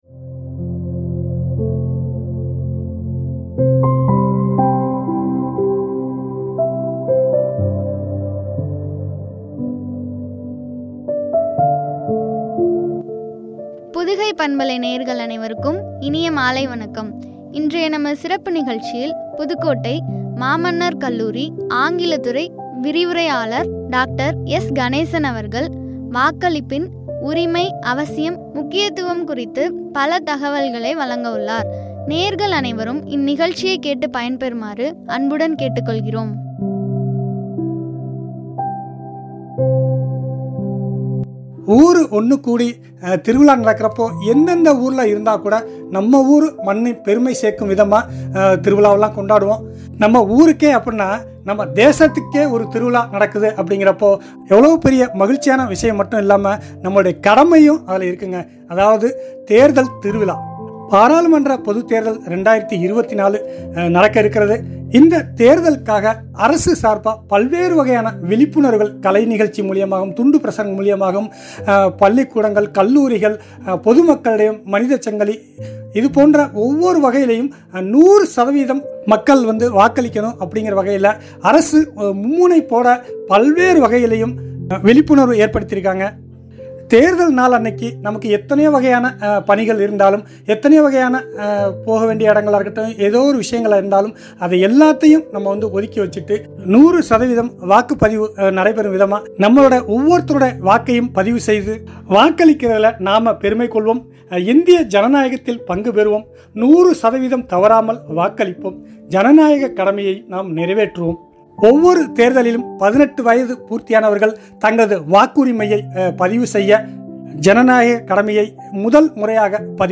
முக்கியத்துவம்” என்ற தலைப்பில் வழங்கிய உரையாடல்.